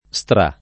Stra+] e Strà [id.] top.